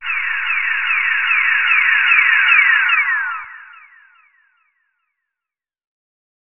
CDK Transition 6.wav